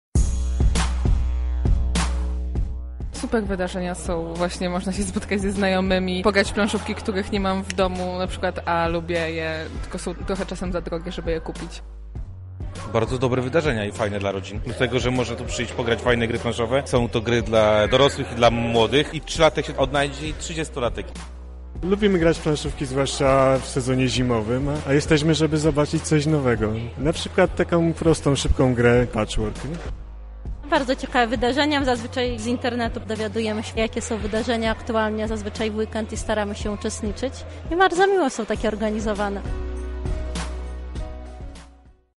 Nasza reporterka zapytała graczy o to co sądzą o tego typu wydarzeniach.